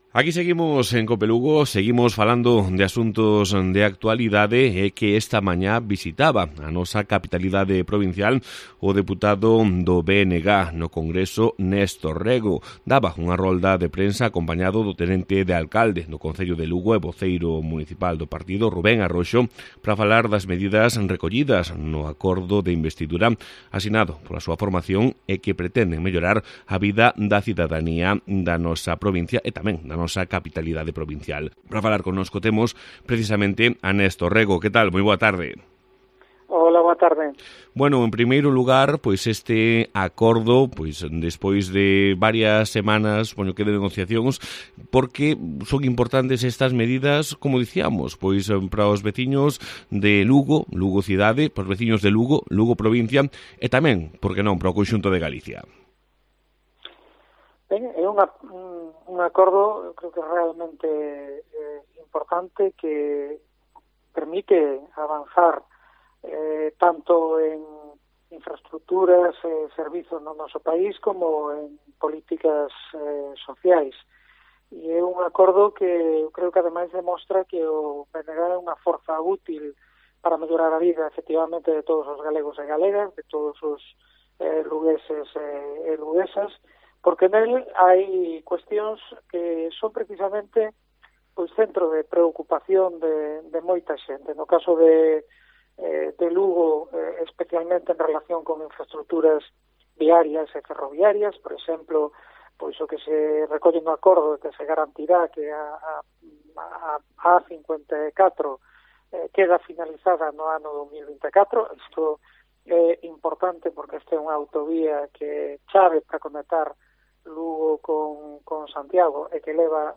AUDIO: El diputado del BNG en el Congreso desgranó en una entrevista concedida a COPE Lugo los puntos del acuerdo de investidura firmado con el PSOE...